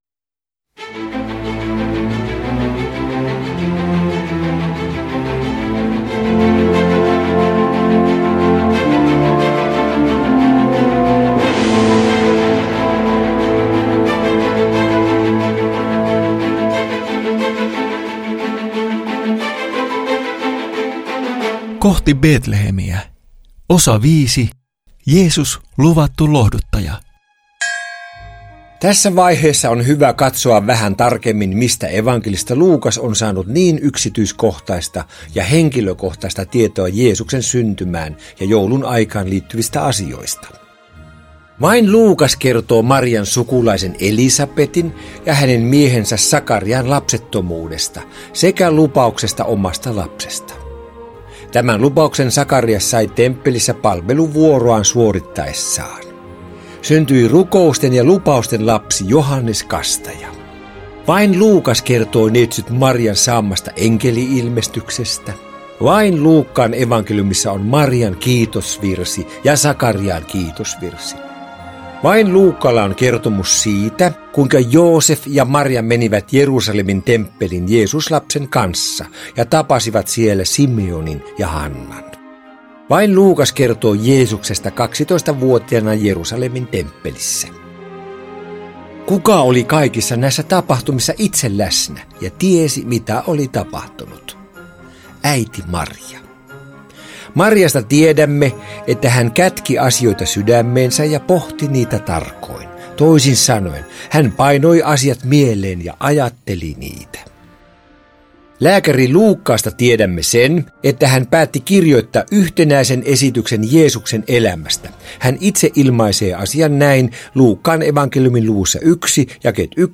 Kohti Betlehemiä - kuunnelmallinen opetussarja joulun tapahtumista - Kristityt Yhdessä ry